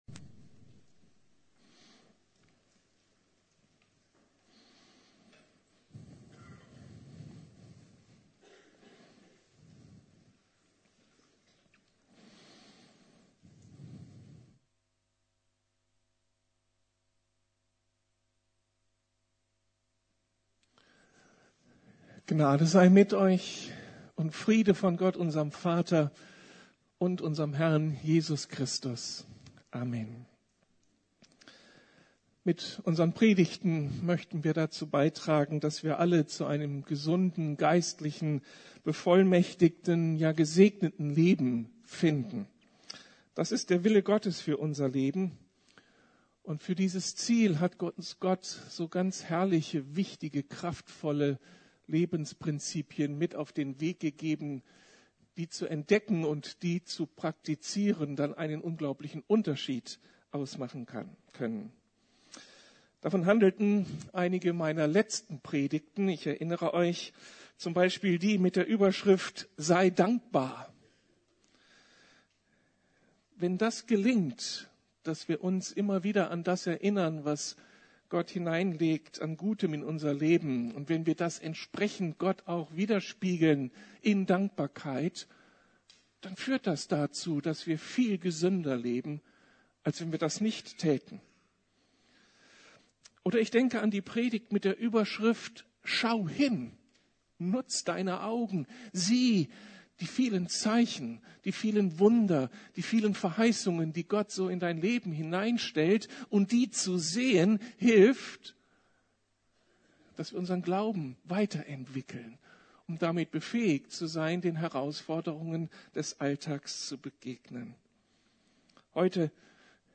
Sei präsent- lebe im Heute! ~ Predigten der LUKAS GEMEINDE Podcast